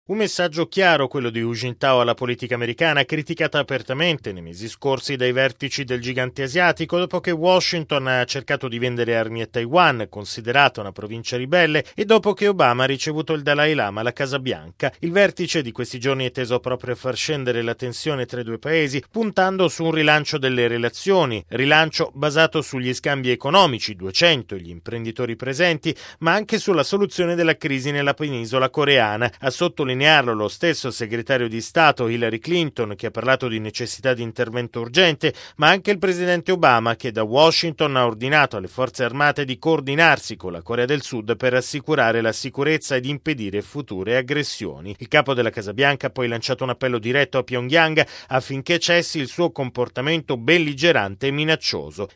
Le tensioni tra le due Coree in primo piano. Il servizio